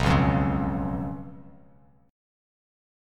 Bsus2b5 chord